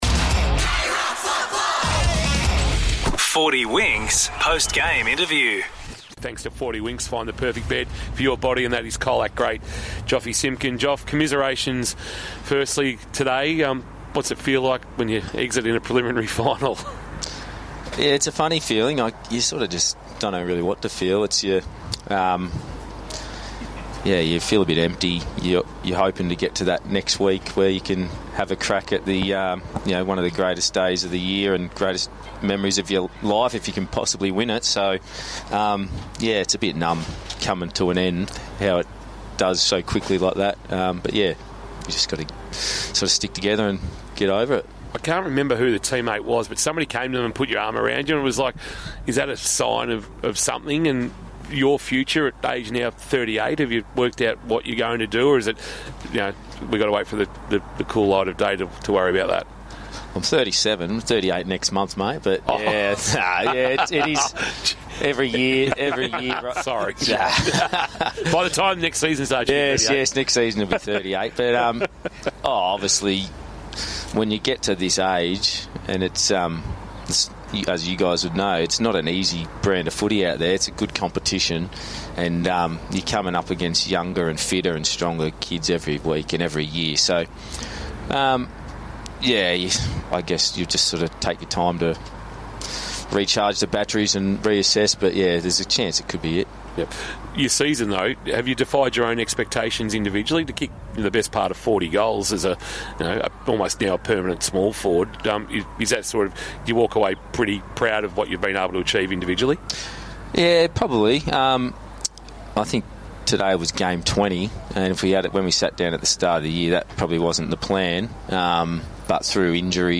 2025 - GFNL - Preliminary Final - Colac vs. St Mary's - Post-match interview